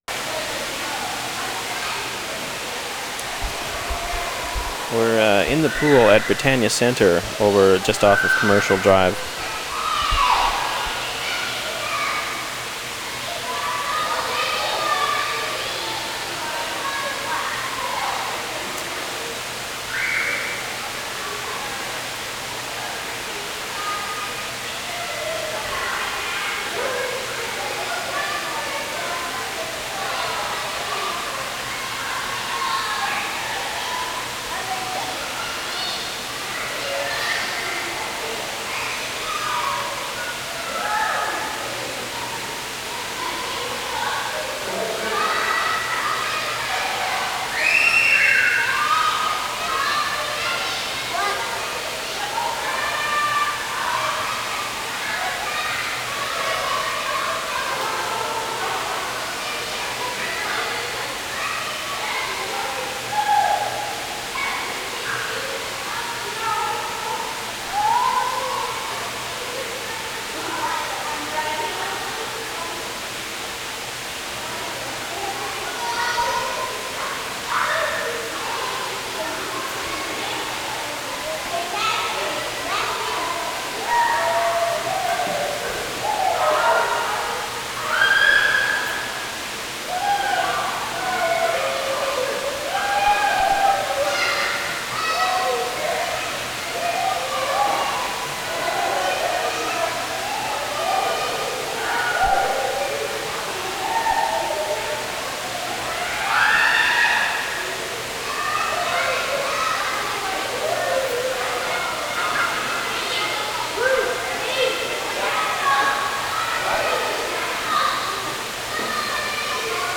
Britannia Centre, swimming pool 5:14
22. ID, kids yelling, water hissing, splashing, hiss drops back at 2:54, instructor, teaching kids, hot levels, walking out at 4:54, whistling, door, pop machine